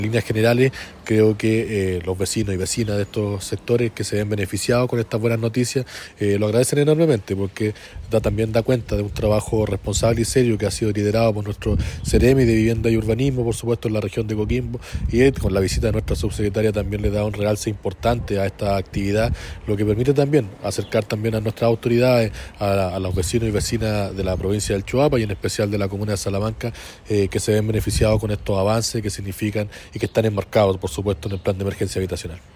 Con respecto a aquello, Jonatan Vega, Delegado Presidencial de Choapa, señaló que “las vecinas y vecinos se dan cuenta de un trabajo responsable y serio que ha sido liderado por el Seremi de Vivienda y Urbanismo, siguiendo los lineamientos del Presidente Gabriel Boric”.